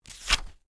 page-flip-16.ogg